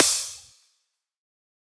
normal-hitfinish.wav